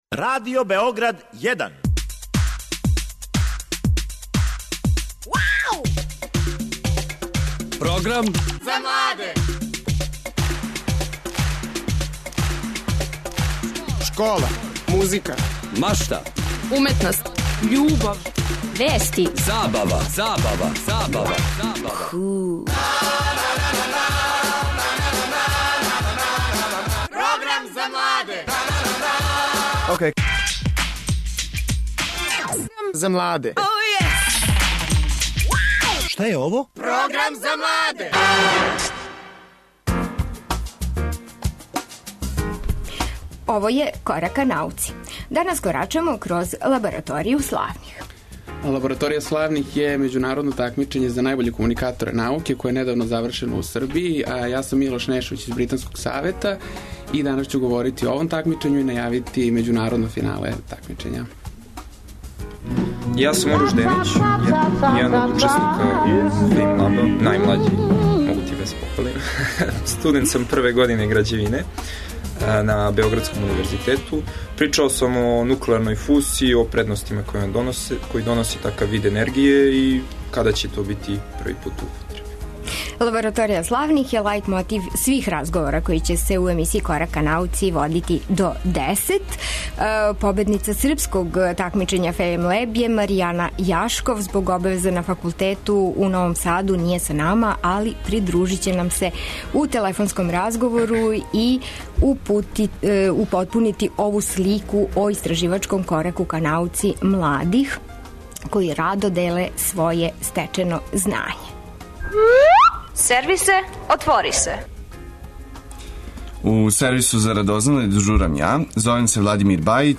На великом одмору водимо вас на сајам науке који се одржава у ОШ "Дринка Павловић".